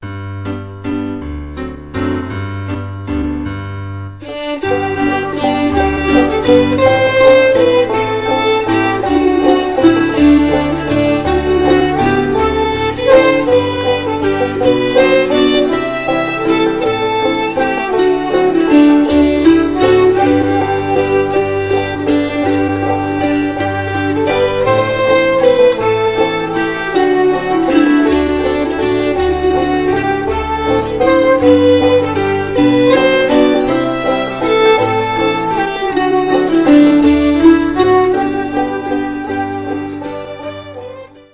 která se zaměřuje na tradiční hudbu amerických osadníků.